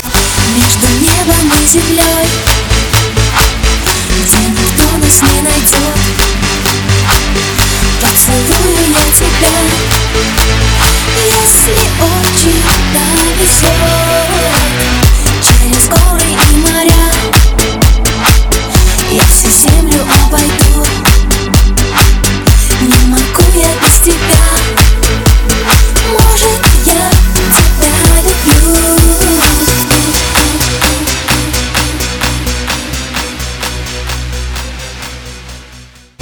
Главная » Файлы » Клубные рингтоны